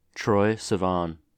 Troye Sivan Mellet (/trɔɪ sɪˈvɑːn/
En-us-Troye_Sivan.oga.mp3